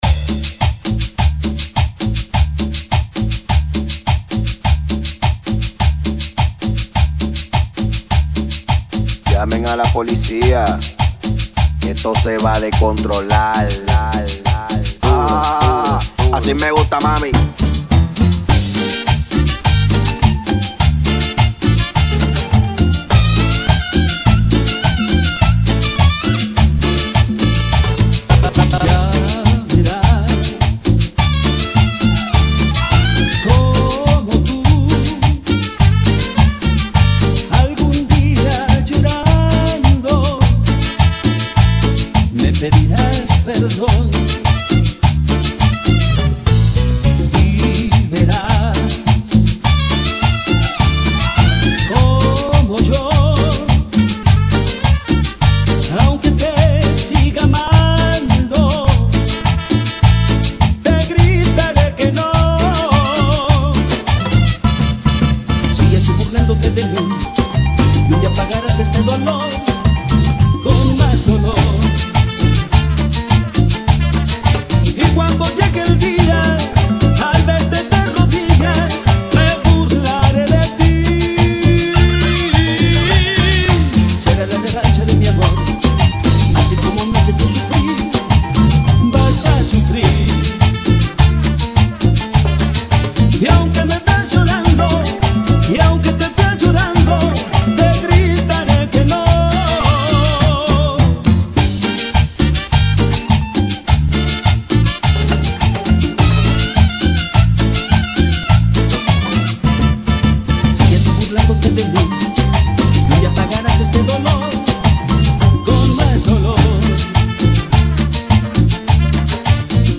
Cumbia Remix